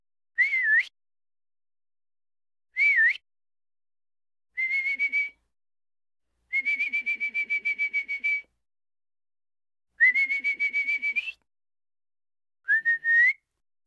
Звуки свиста, свистков
Свист девушки или свист женщины